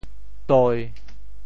隄 部首拼音 部首 阝 总笔划 11 部外笔划 9 普通话 dī 潮州发音 潮州 doi1 文 中文解释 堤 <名> (形声。